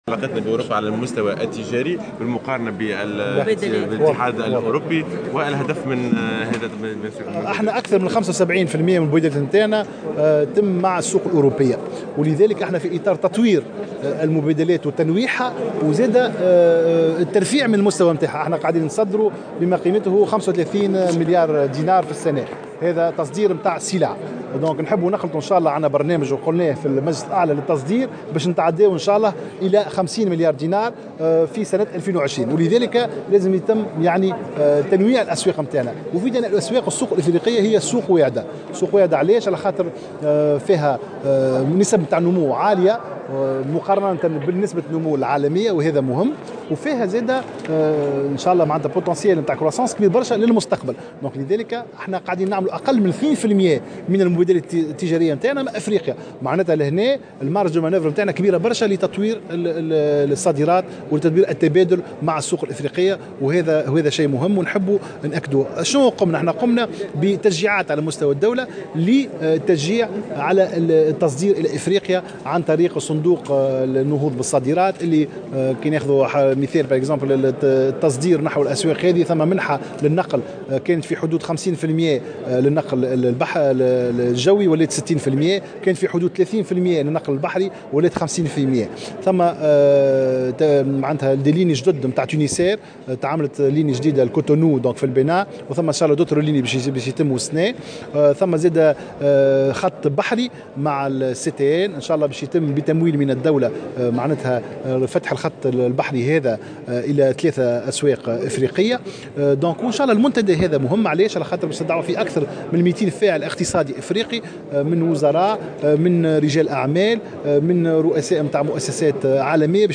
وفي تعليقه على وقفة احتجاجية ينظمها أصحاب معاصر الزيتون، أوضح الوزير في تصريح لمراسلة "الجوهرة أف أم"، أن التمكن من تصدير 115 الف طن امر مهم إلا أنه لا يمكن انهاء عملية التصدير في ظرف 3 أشهر، مضيفا أن عملية التصدير تتطلب 8 أشهر، بحسب تعبيره.